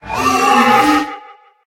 Cri de Dispareptil dans Pokémon HOME.